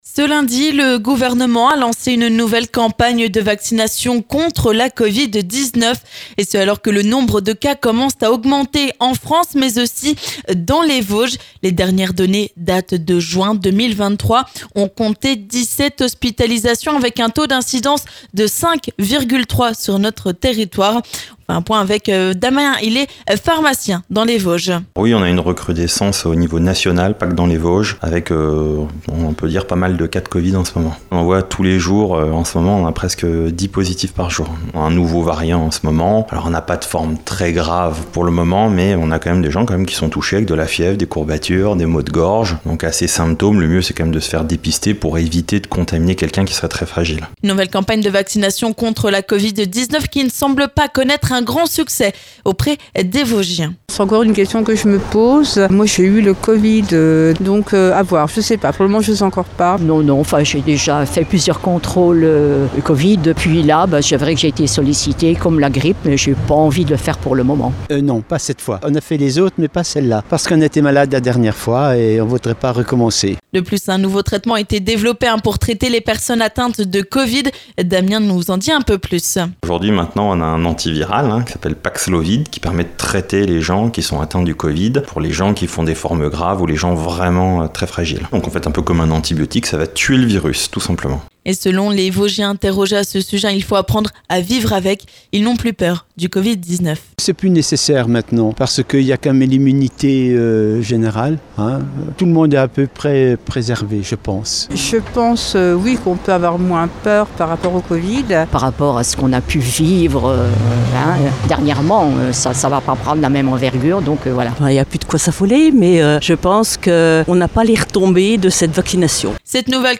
On fait le point avec un pharmacien vosgien, et nous avons demandé l'avis des habitants.